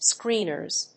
/ˈskrinɝz(米国英語), ˈskri:nɜ:z(英国英語)/